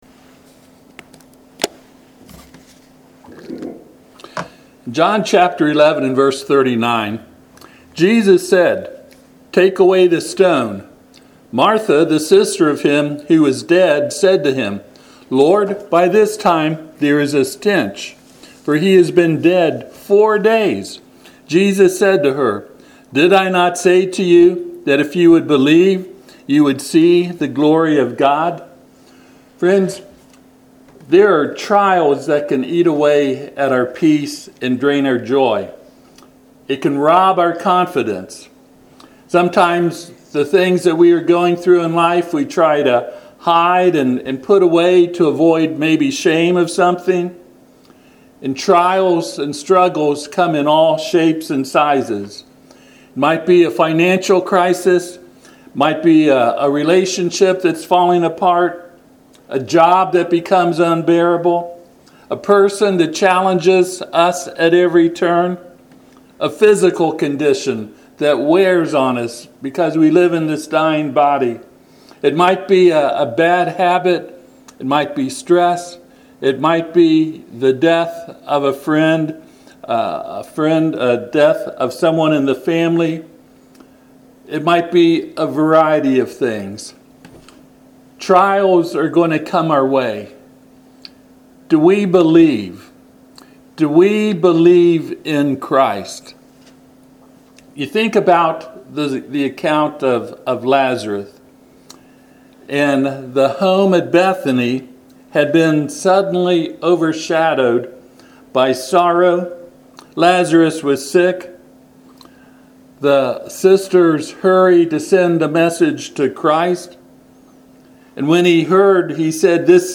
John 11:39-40 Service Type: Sunday AM https